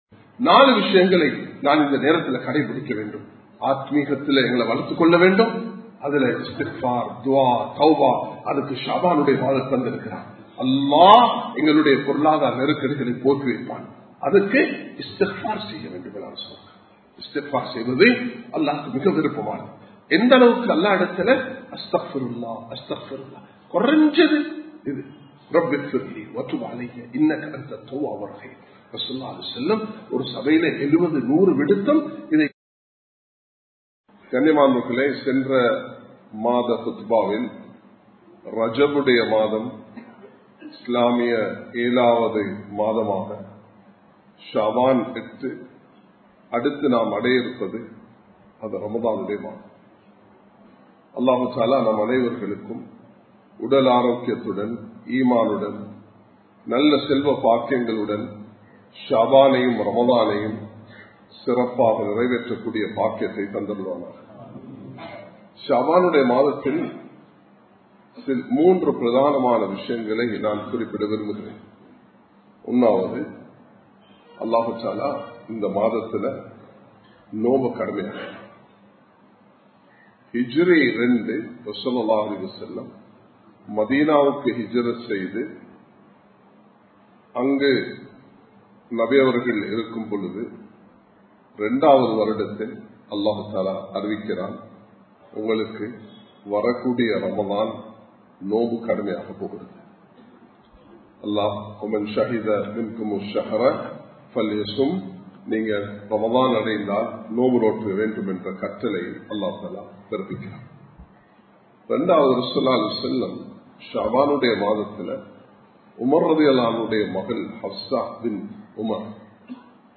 04 விடயங்களை கடைப்பிடிப்போம் | Audio Bayans | All Ceylon Muslim Youth Community | Addalaichenai
Samman Kottu Jumua Masjith (Red Masjith)